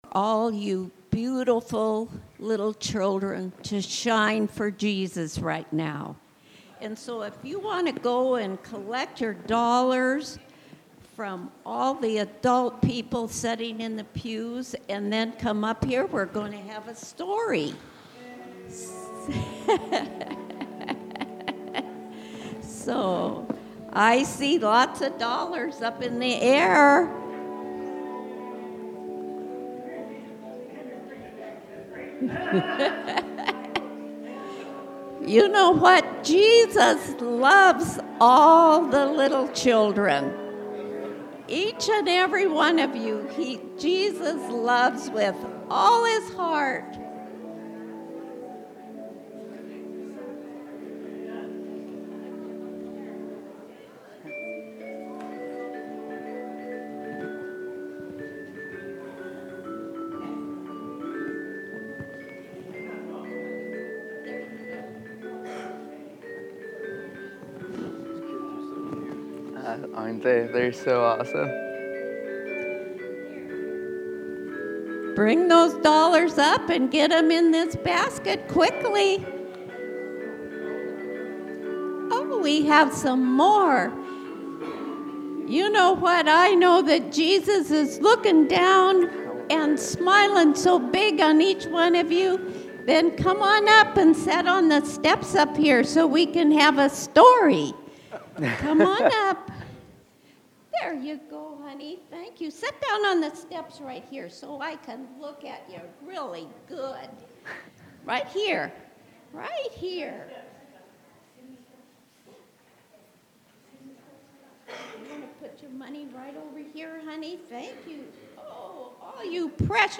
Past Sermons